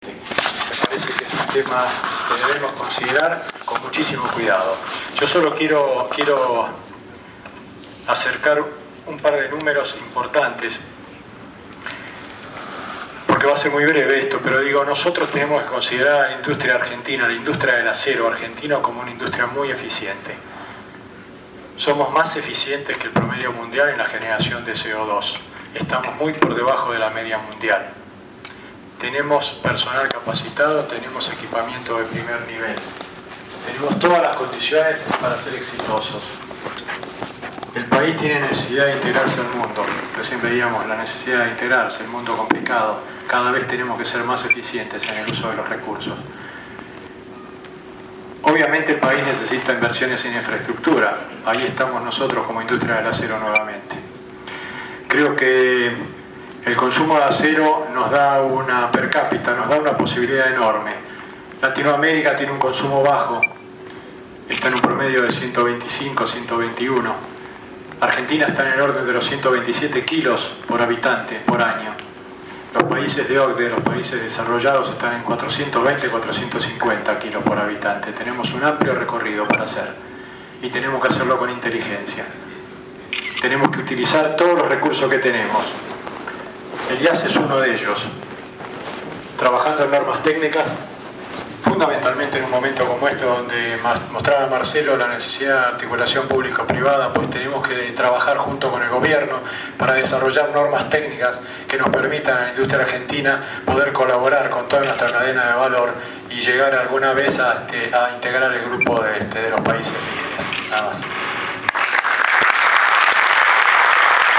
La 21ª Conferencia del Acero IAS y Expo IAS 2016.